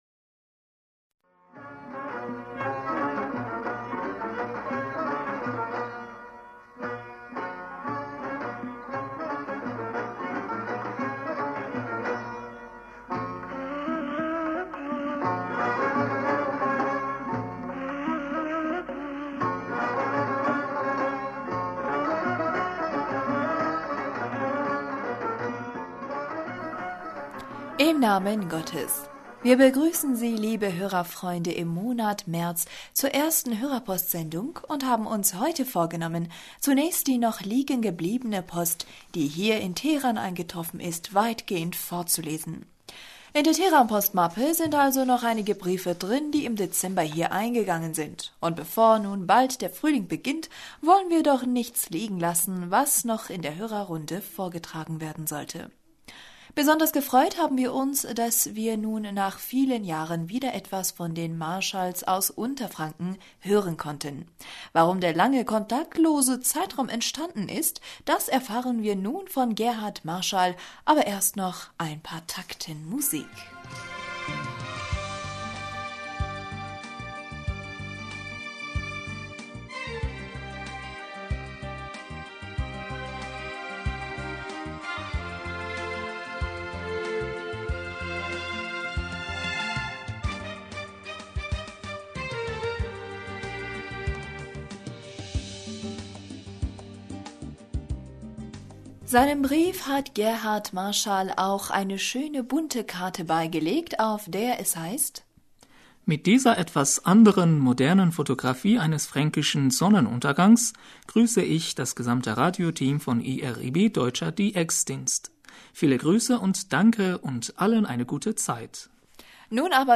Hörerpostsendung am 06.03.2016 In der Teheran-Postmappe sind also noch einige Briefe drin, die im Dezember hier eingegangen sind, und bevor nun bald der Frühling beginnt, wollen wir doch nichts liegen lassen, was noch in der Hörerrunde vorgetragen werden sollte.